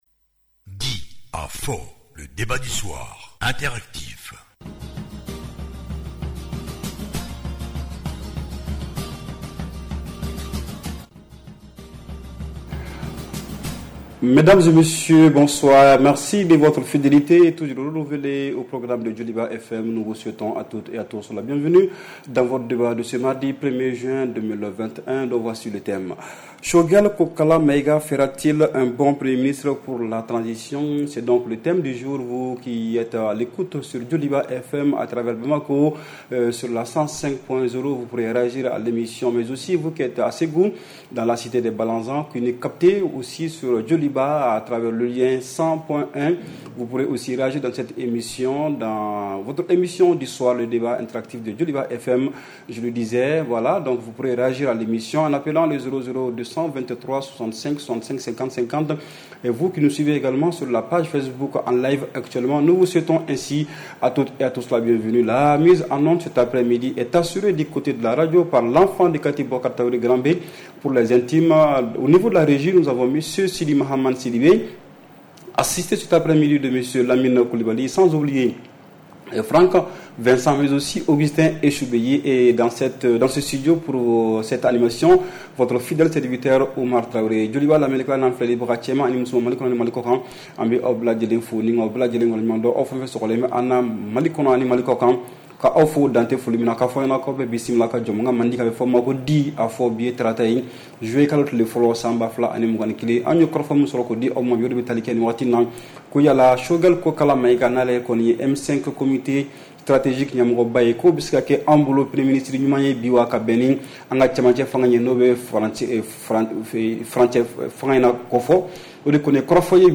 REPLAY 01/06 – « DIS ! » Le Débat Interactif du Soir